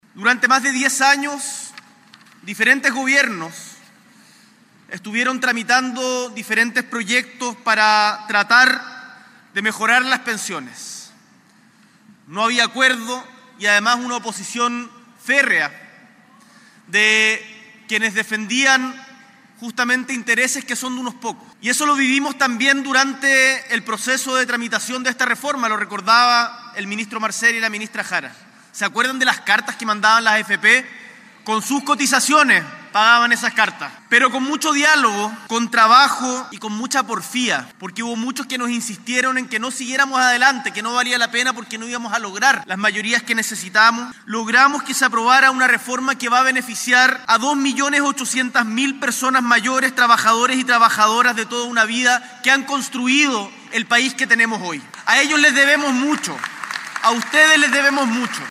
La actividad se realizó el jueves 20 de marzo en el Centro Cultural y Deportivo Chimkowe de Peñalolén.